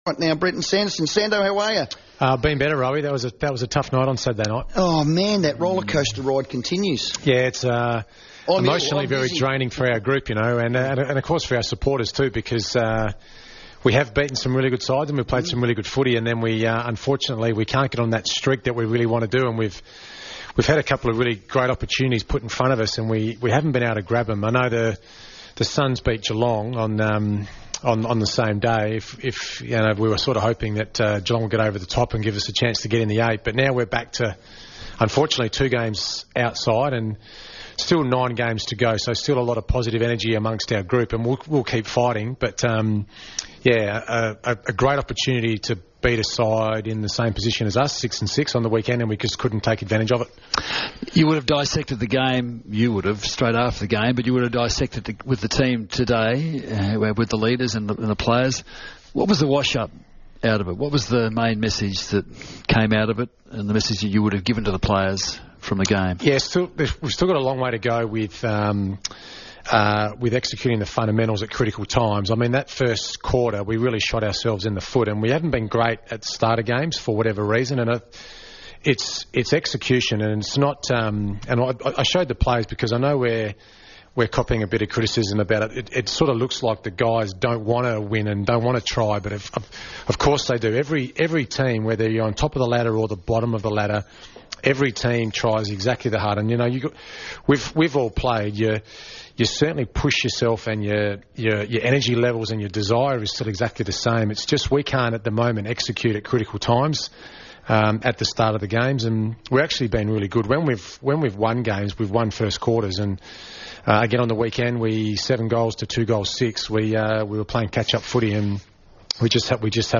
Live from the Club's Great Footy Pie Night, Brenton Sanderson dissects Adelaide's disappointing result against the Bombers.